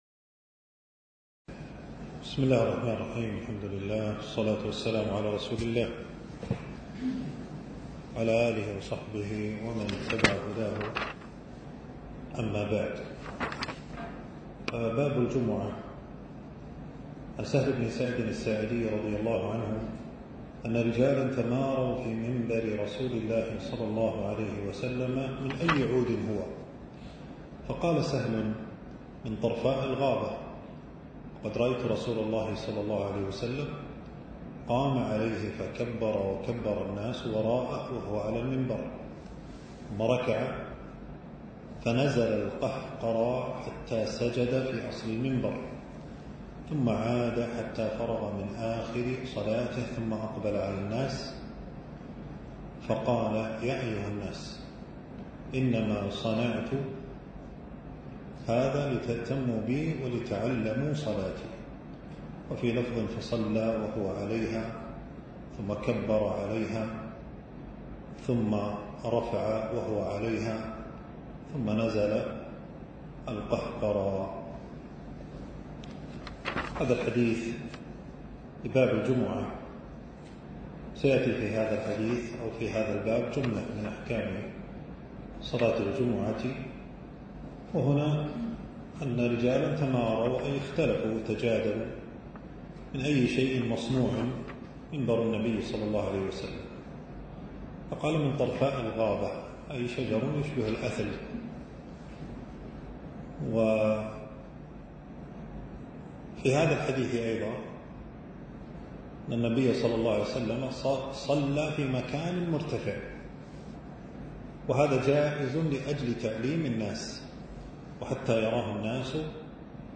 المكان: درس ألقاه في 4 جمادى الثاني 1447هـ في مبنى التدريب بوزارة الشؤون الإسلامية.